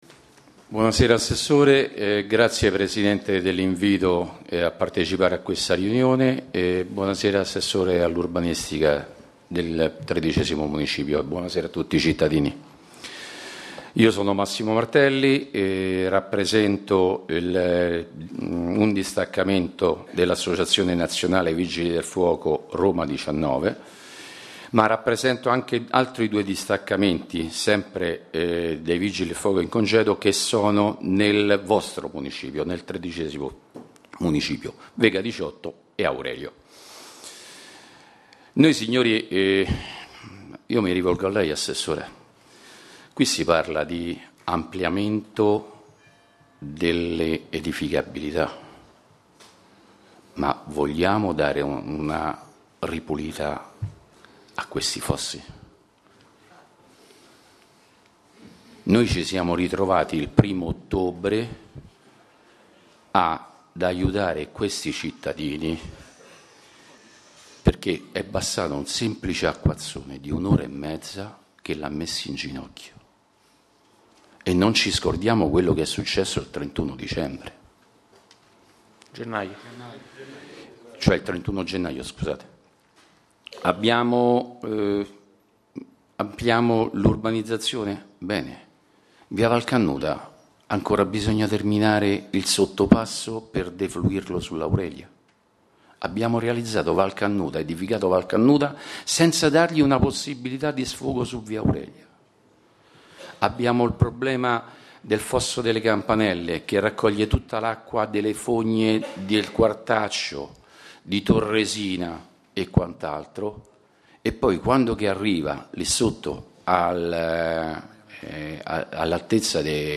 Registrazione integrale dell'incontro svoltosi il 16 ottobre 2014 presso la sala consiliare del Municipio XIII, in Via Aurelia, 474.